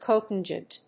音節co・tan・gent 発音記号・読み方
/kòʊtˈændʒənt(米国英語)/